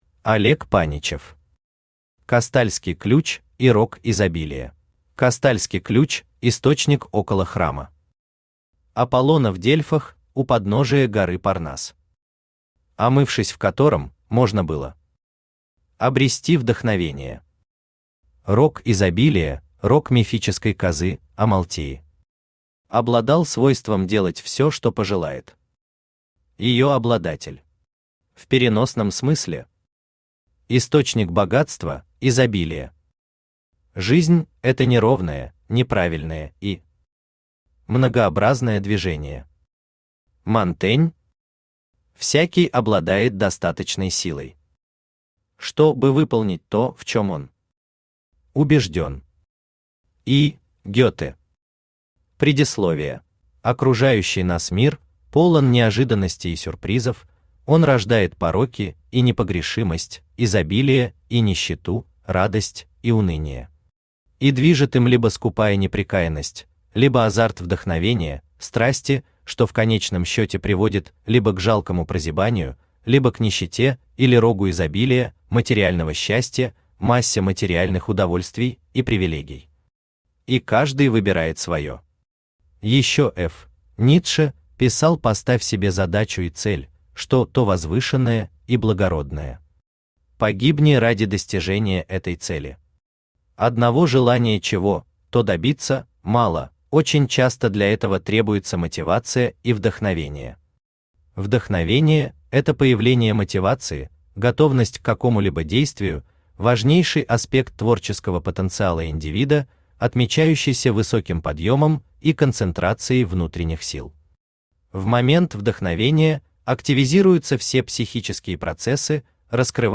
Аудиокнига Кастальский ключ и рог изобилия | Библиотека аудиокниг
Aудиокнига Кастальский ключ и рог изобилия Автор Олег Владимирович Паничев Читает аудиокнигу Авточтец ЛитРес.